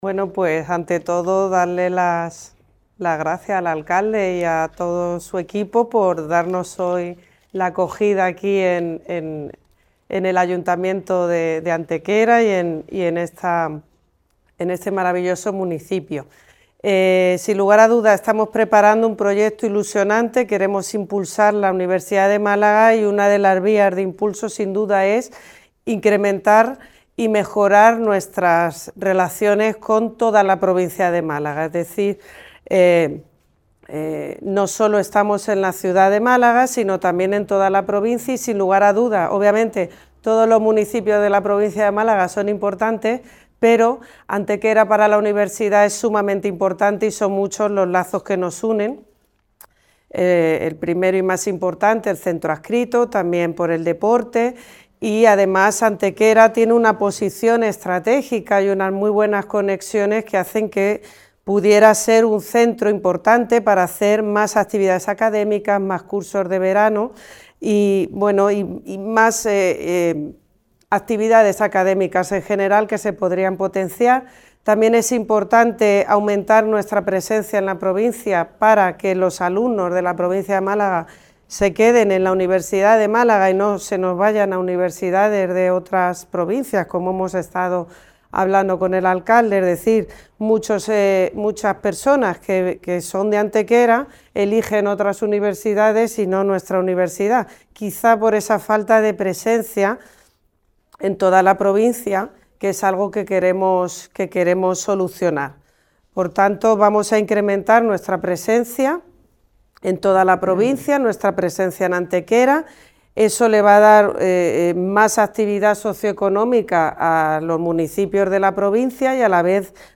En una rueda de prensa celebrada hoy en la Universidad de Málaga (UMA)